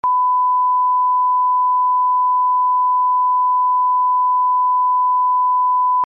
FLV Ingest Test - Bars & Tone